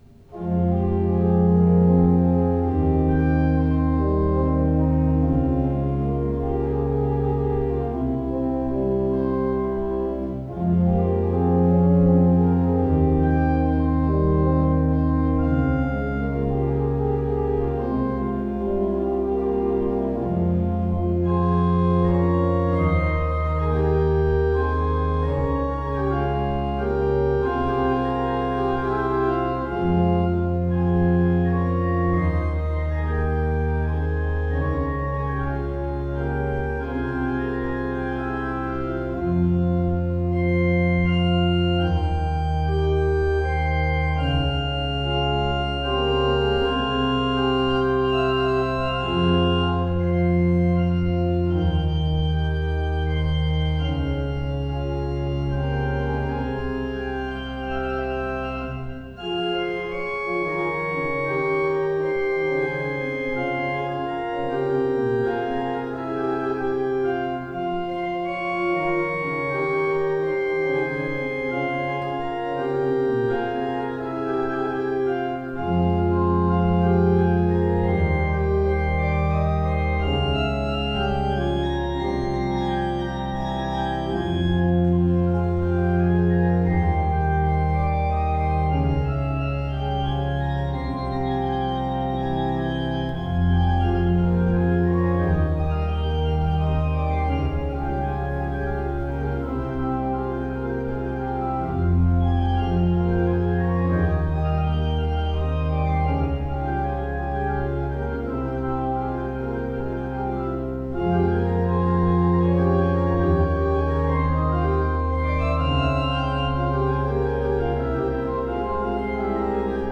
Postludes played at St George's East Ivanhoe 2016
The performances are as recorded on the Thursday evening prior the service in question and are made using a Zoom H4 digital recorder.